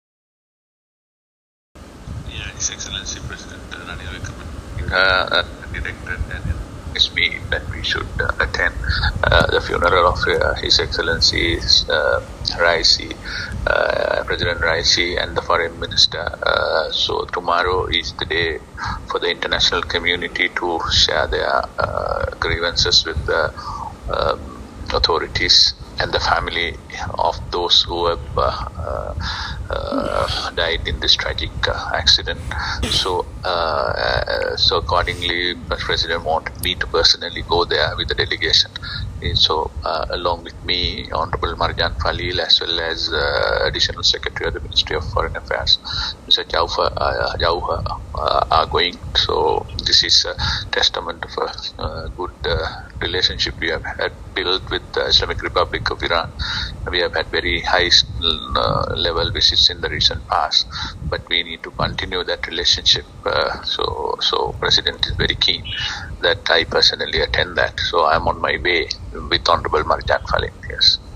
Speaking to Colombo Times while going to the airport , Minister Sabry said he was going on this sad journey on a request made by the Sri Lankan President Ranil Wickremsinghe who wanted Sri Lanka to take part in the bereavement and share the sorrows with the Iranian people who had suffered an irreparable loss.